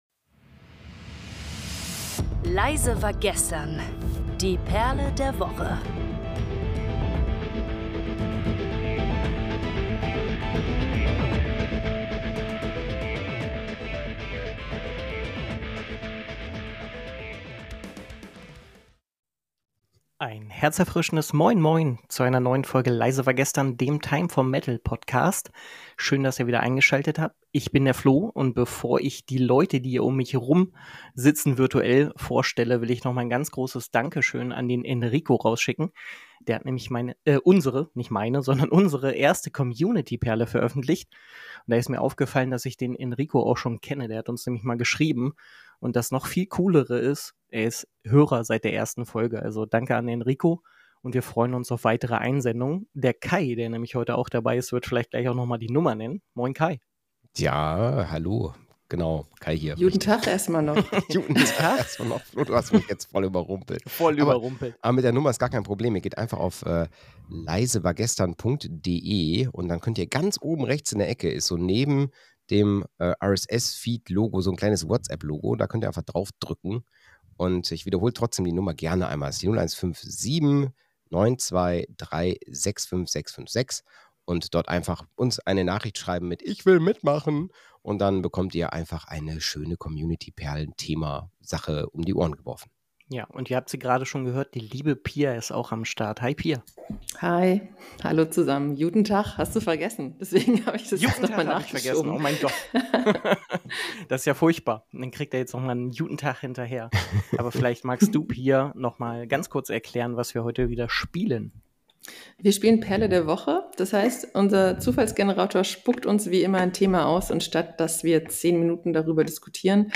Die Moderatoren präsentieren jeweils einen Song mit dem Buchstaben 'Z': 'Zaphyr' von Aries, 'Zombiefied' von Falling Reverse und 'Zero Hour' von I.Q..